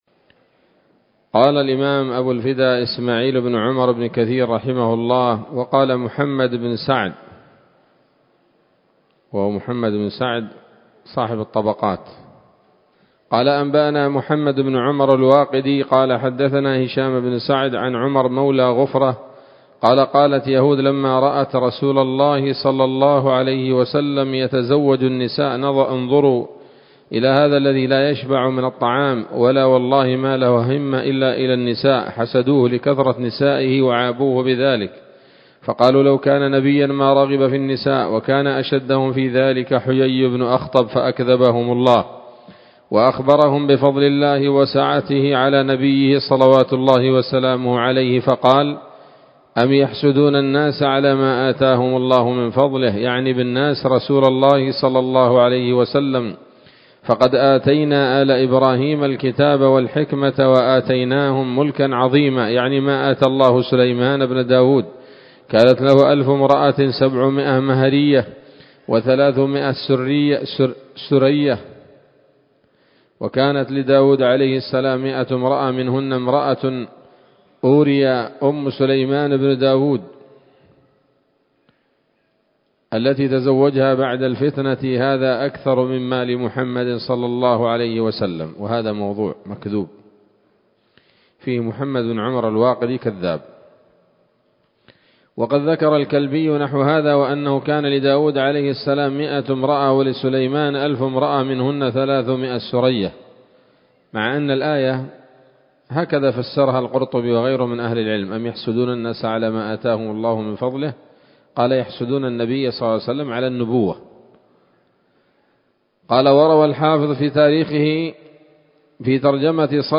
‌‌الدرس العشرون بعد المائة من قصص الأنبياء لابن كثير رحمه الله تعالى